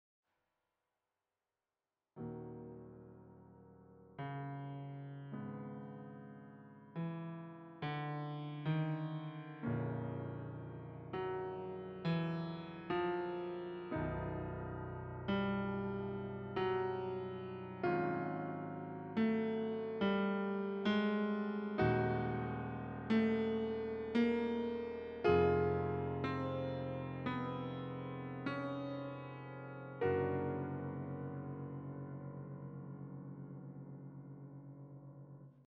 Jest nim progresja wywiedziona ze zwrotu zastosowanego w części drugiej – kiedy zza horyzontu wyłonił się żagiel. Jak wielokrotnie w tym utworze, progresja jest w dwóch postaciach: skierowana w dół i w górę, w lustrzanym odbiciu względem d-gis, w obu przypadkach z zakończeniem na akordzie zwiększonym d-fis-b:
Motywy melodyczne tej progresji są pokrewne motywom obecnym w śpiewie – zarówno tutaj, jak i wcześniej, w obu poprzednich częściach.